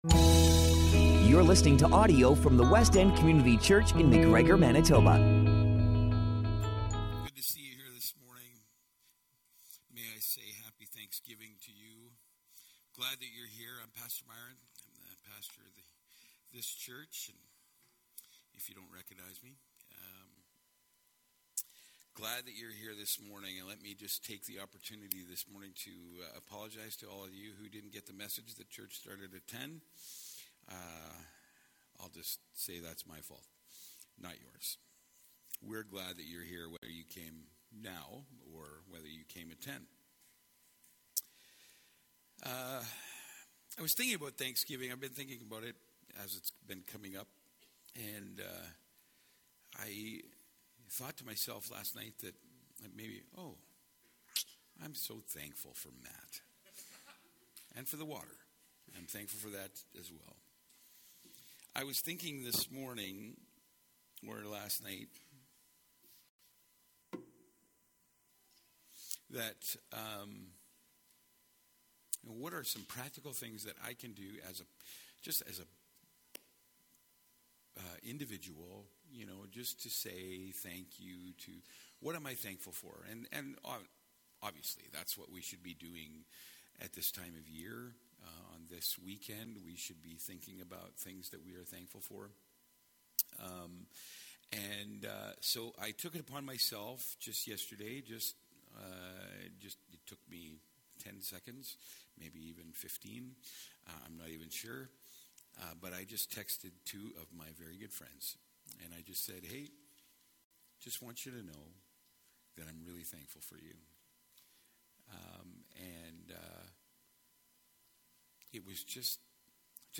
Sermons - Westend Community Church